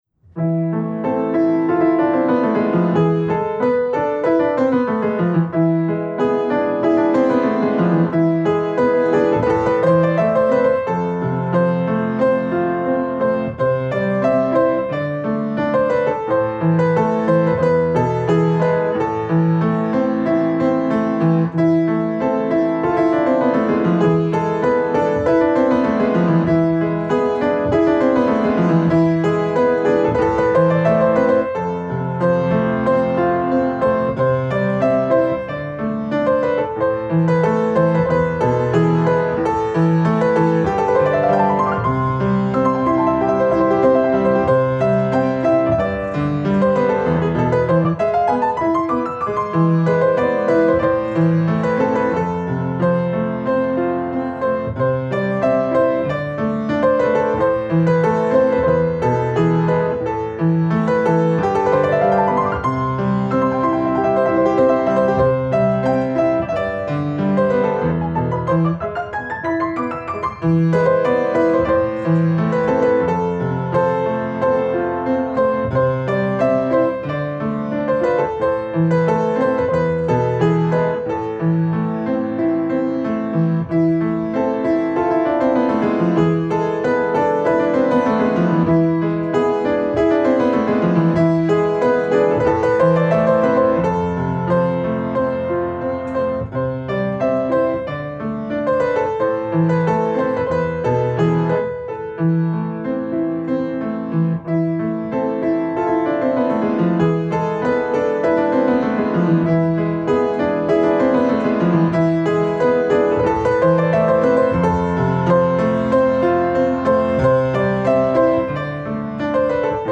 הפסנתרנית